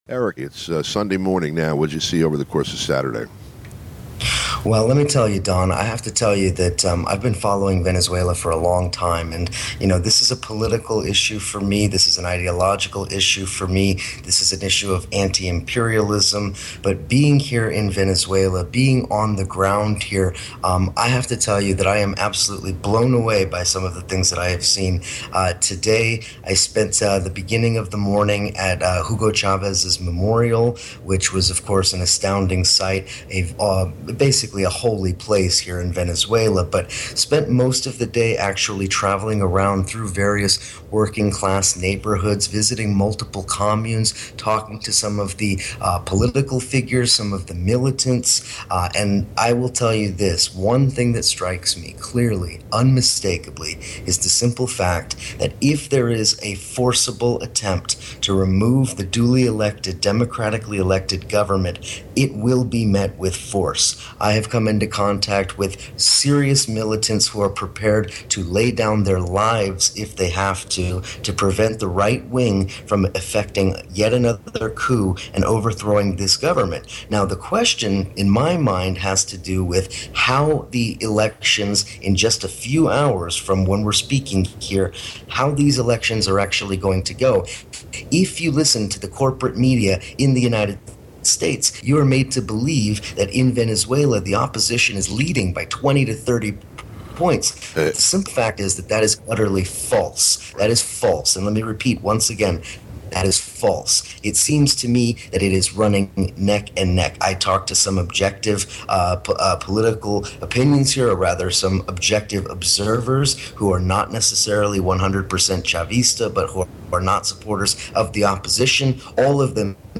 Program Type: Interview Speakers